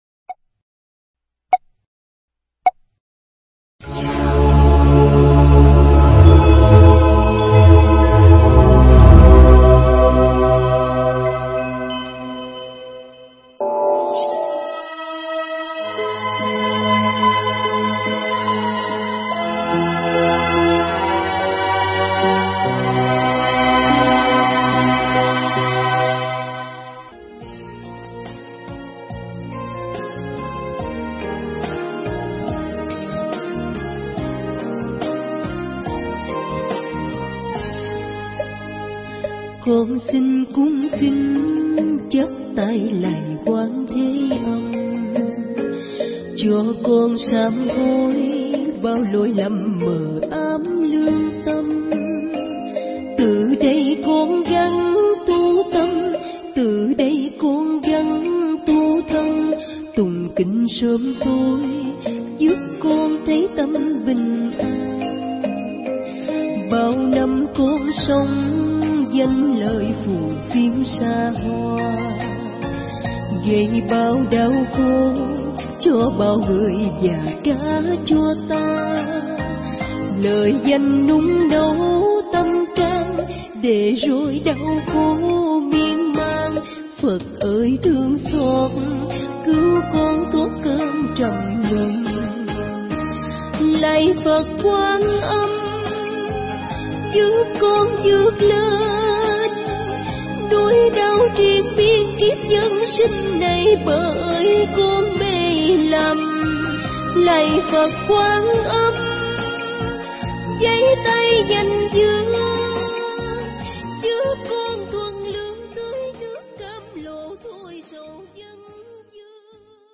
Mp3 Pháp Thoại Chuyển Tâm Tức Thời Chuyển Nghiệp
Giảng Tại Thiền Viện Ngọc Hạnh, Trường An, Vĩnh Long